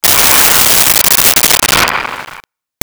Ahhhh Breath
Ahhhh Breath.wav